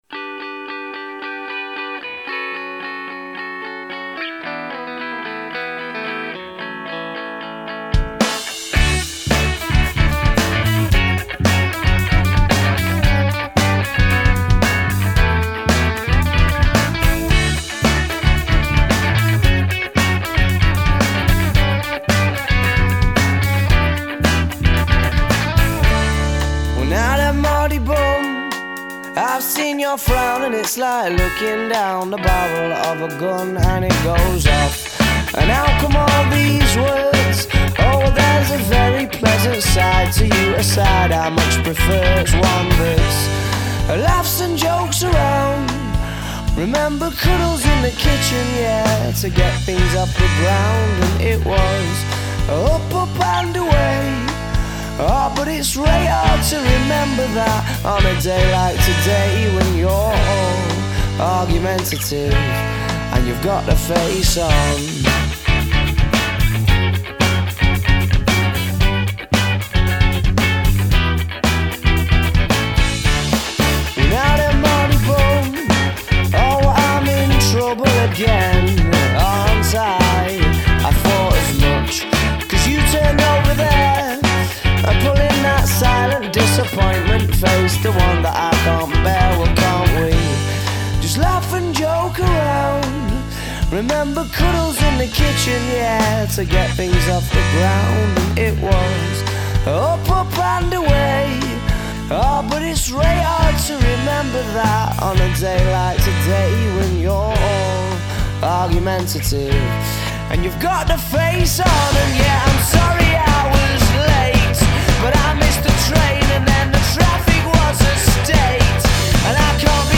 The tone was dead on too.